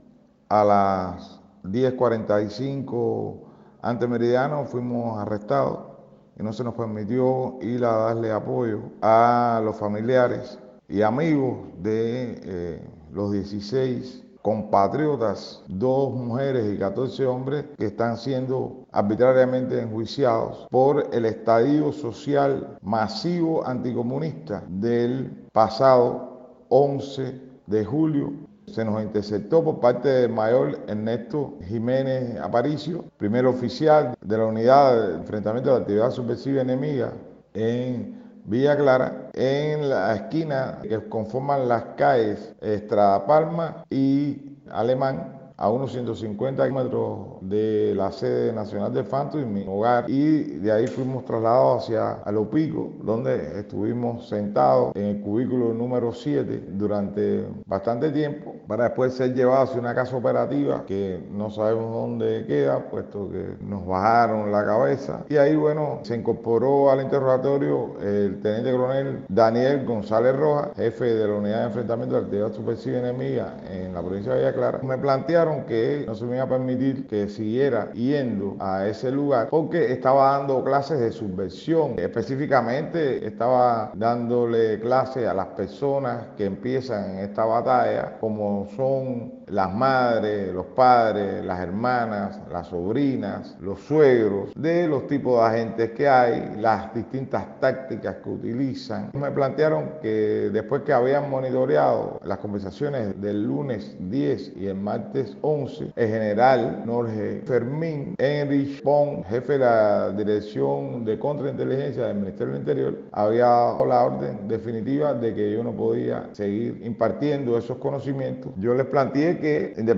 Declaraciones de Guillermo Fariñas